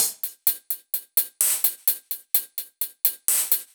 Index of /musicradar/french-house-chillout-samples/128bpm/Beats
FHC_BeatB_128-02_Hats.wav